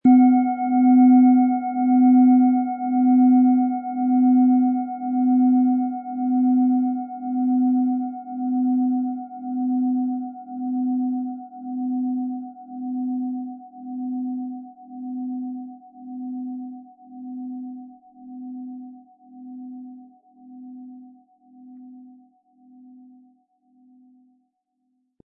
• Mittlerer Ton: Wasser
PlanetentöneSonne & Wasser
MaterialBronze